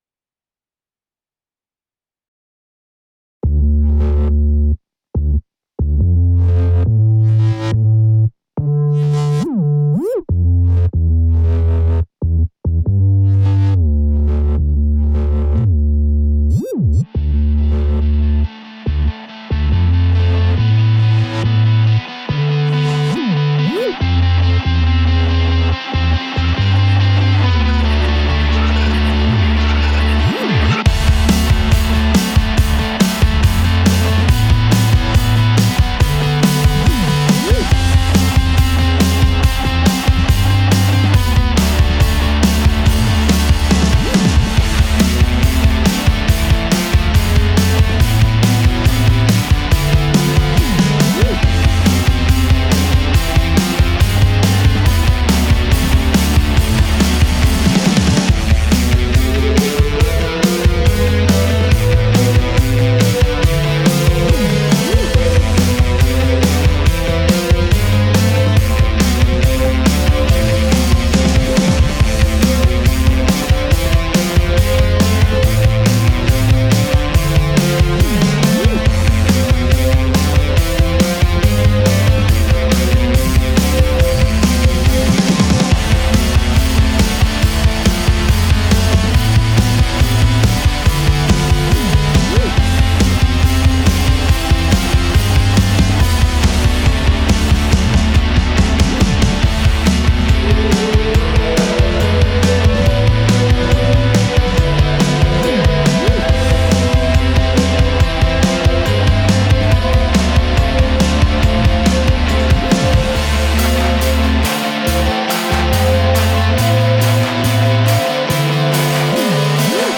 je vous présente un réarrangement "Post Rock"